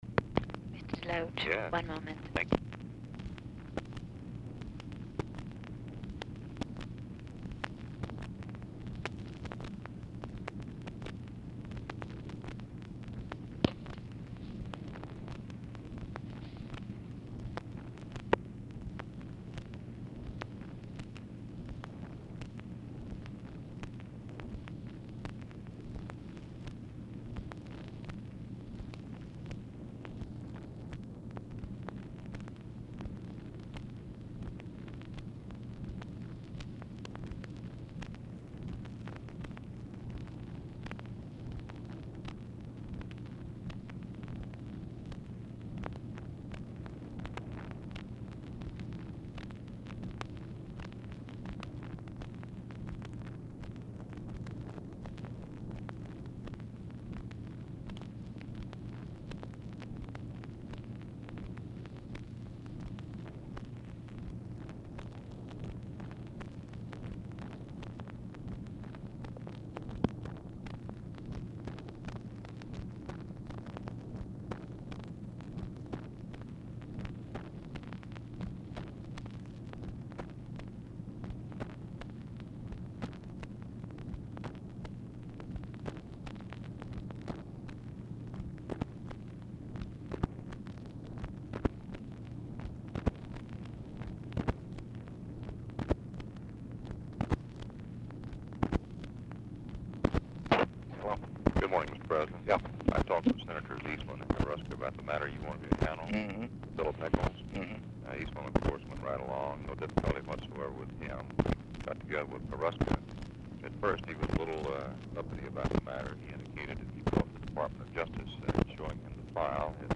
DELOACH ON HOLD 1:40
Format Dictation belt
Location Of Speaker 1 Oval Office or unknown location
Specific Item Type Telephone conversation